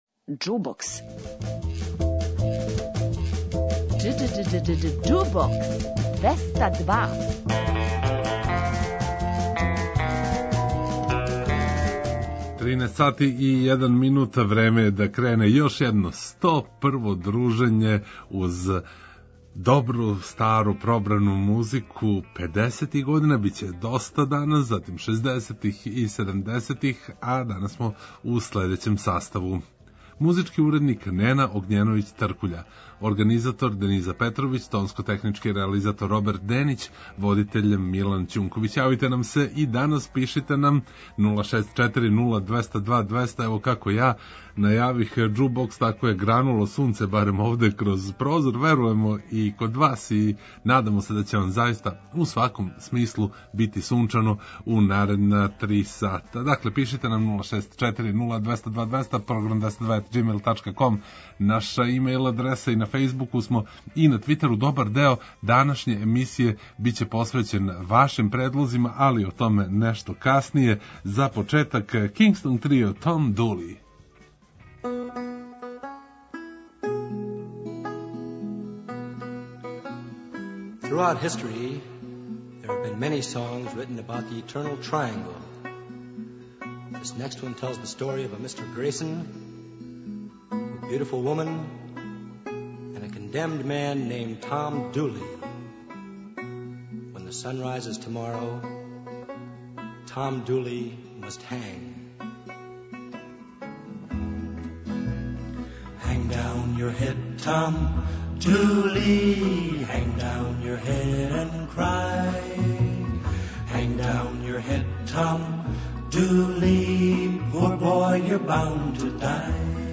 У сусрет новим јубилејима, очекује вас 101. издање наше емисије где ћемо, после дужег времена, поново да „промешамо” педесете, шездесете и седамдесете године. Слушаћете и стране и домаће нумере.